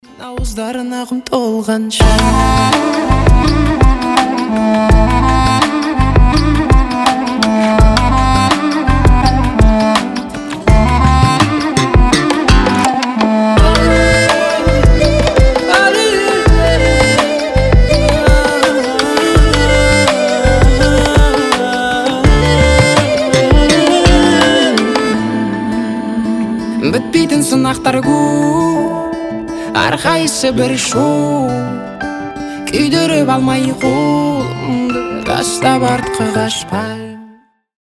• Качество: 320, Stereo
красивые
мелодичные
медленные
восточные
казахские
дудук